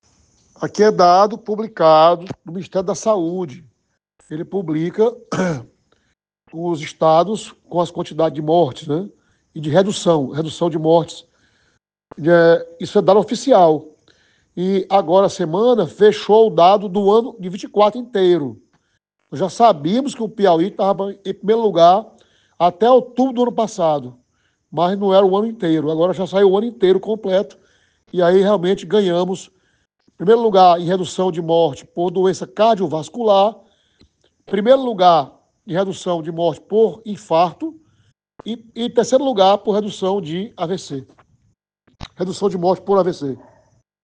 Ouça a seguir o que disse o secretário Antônio Luiz.